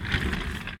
roll.ogg